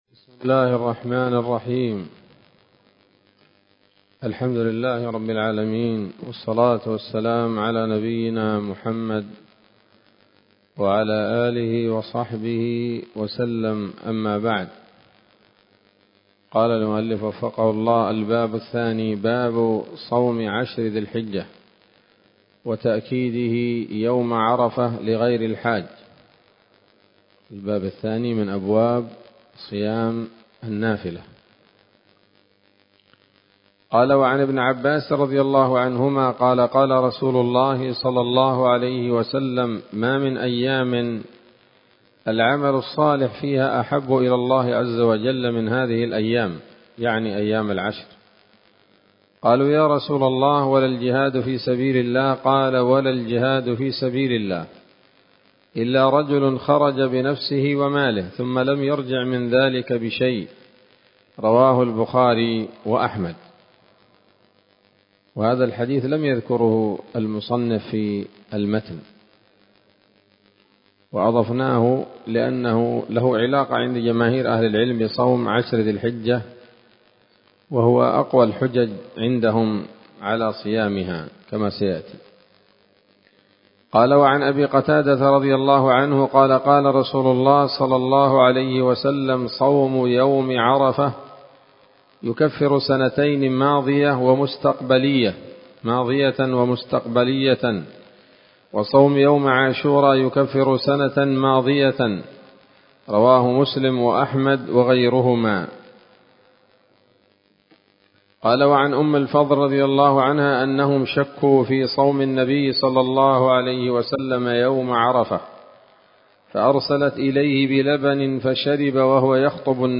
الدرس الحادي والعشرون من كتاب الصيام من نثر الأزهار في ترتيب وتهذيب واختصار نيل الأوطار